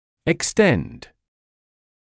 [ɪk’stend][ик’стэнд]простираться, доходить до; растягивать(ся)